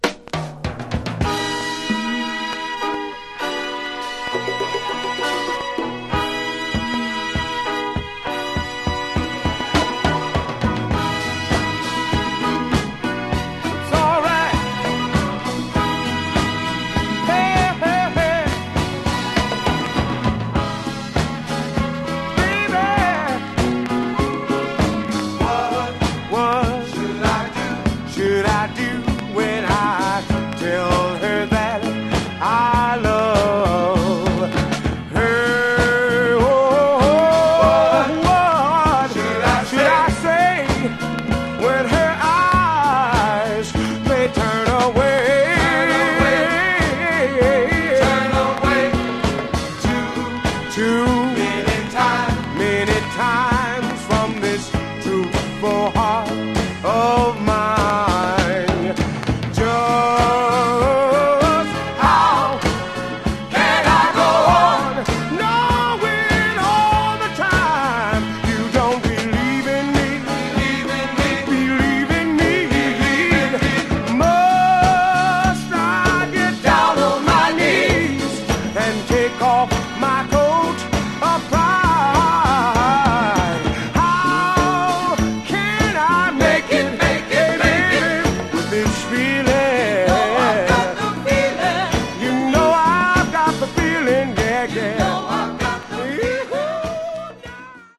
Genre: Northern Soul, Philly Style